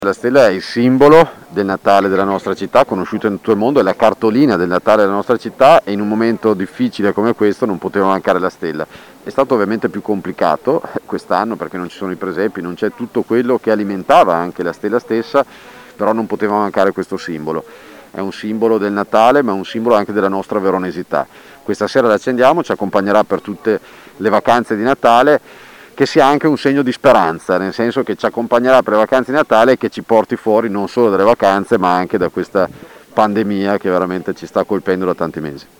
Le interviste del nostro corrispondente
sindaco di Verona, Federico Sboarina
Accensione-della-Stella-di-Natale-a-Verona-il-sindaco-Federico-Sboarina.mp3